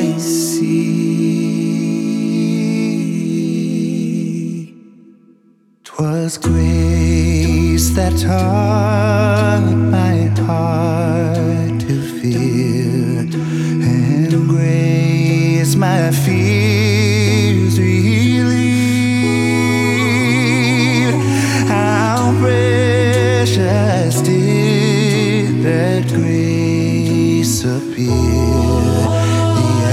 • Christian